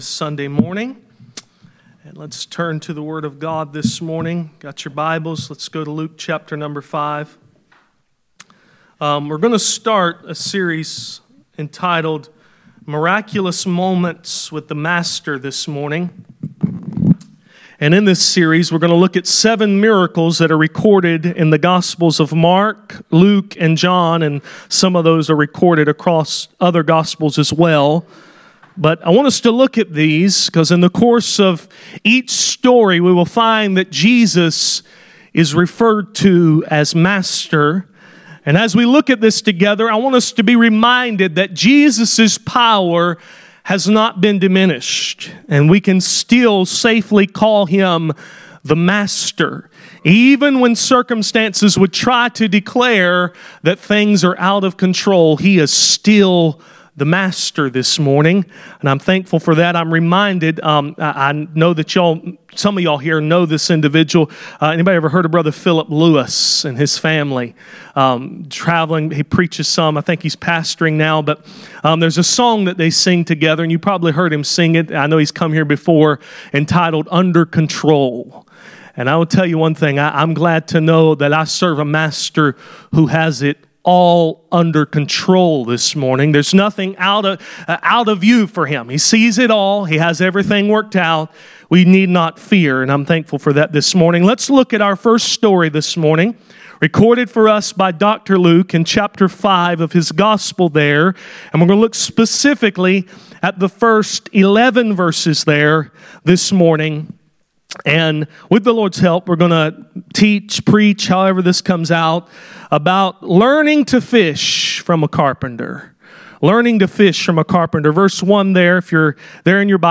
Luke 5:1-11 Service Type: Sunday Morning « The Sower